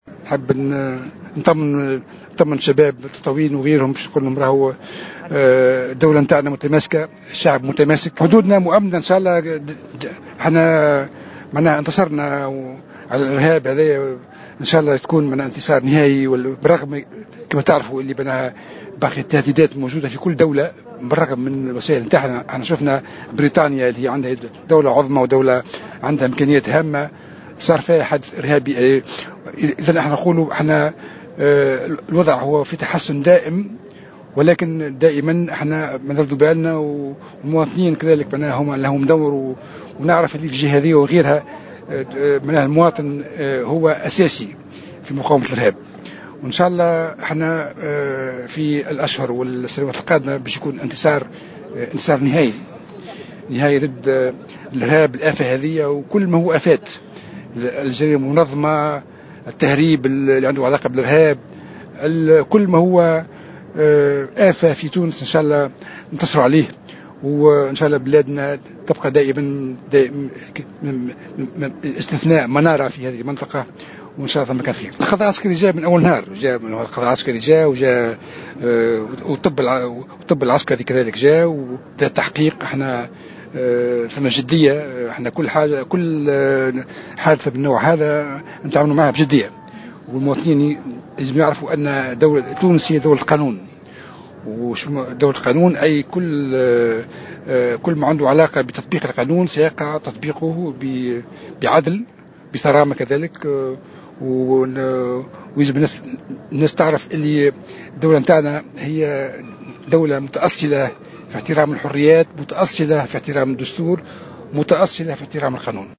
وزير الدفاع الوطني